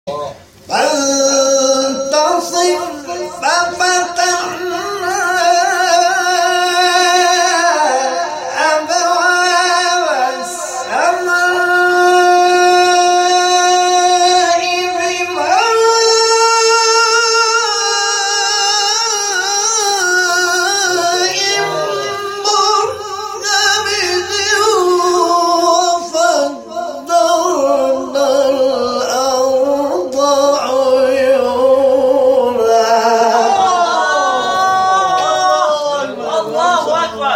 گروه شبکه اجتماعی: فرازهای صوتی از قاریان ممتاز و تعدادی از قاریان بین‌المللی کشورمان را می‌شنوید.